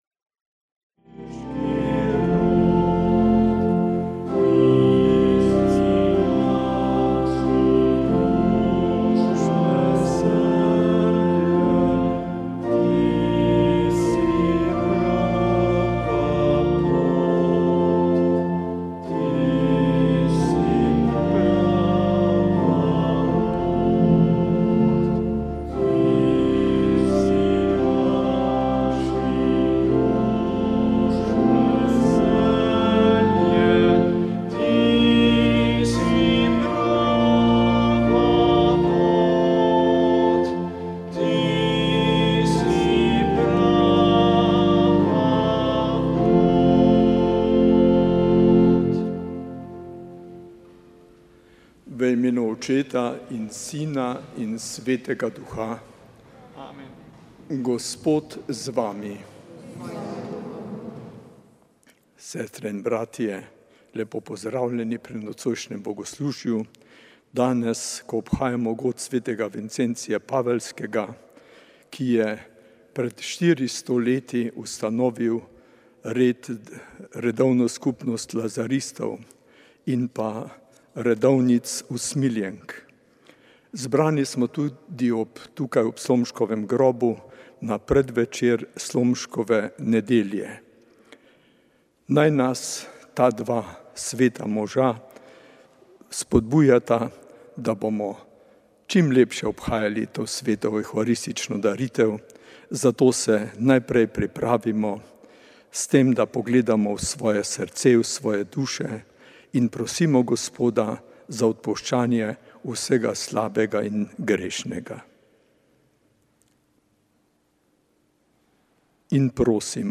Sveta maša
Posnetek svete maše iz mariborske stolnice
Iz mariborske stolnice svetega Janeza Krstnika smo na peto velikonočno nedeljo prenašali posnetek svete maše, ki jo je ob somaševanju stolnih duhovnikov daroval mariborski nadškof Alojzij Cvikl.